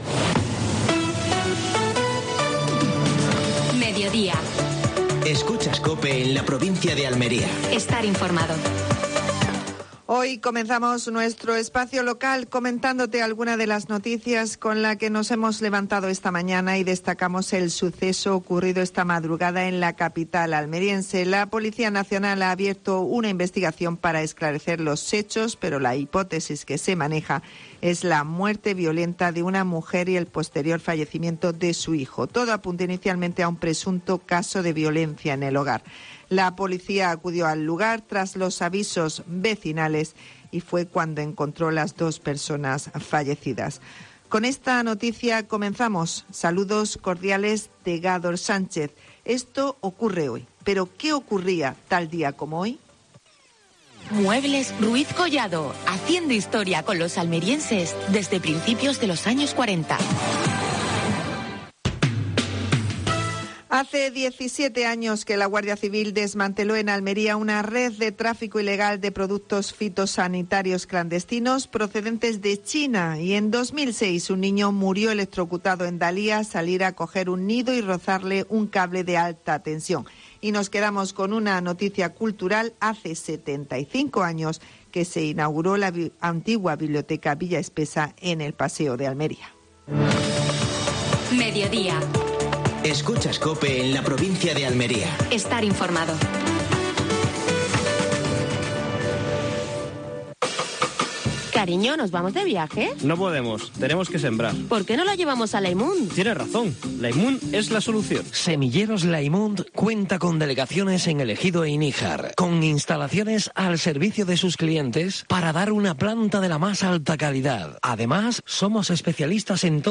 AUDIO: Actualidad en Almería. Entrevista a Agro San Isidro.